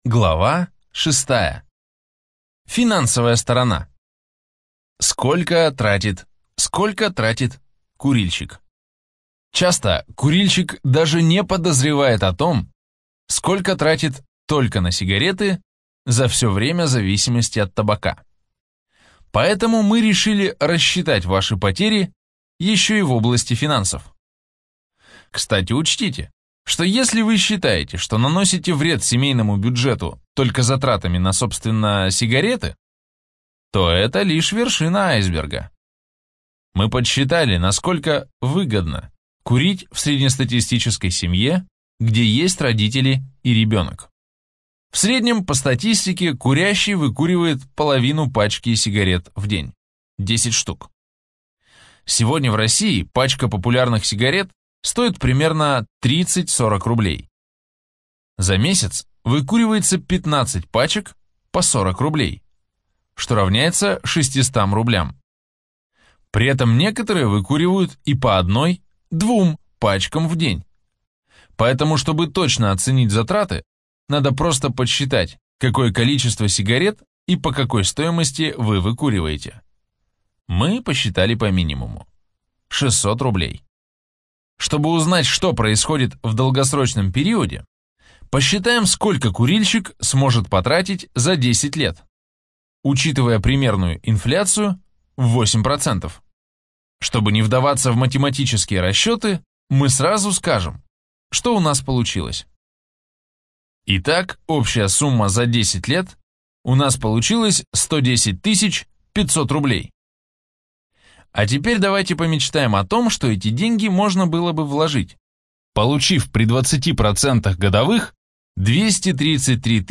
Аудиокнига Как легко и быстро бросить курить | Библиотека аудиокниг